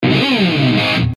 ロックな効果音をご自由にダウンロードして下さい。
Distortion Sound Guitar
Distortionブオーン…ジャ(A♭)01 22.94 KB